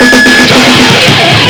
noisecore,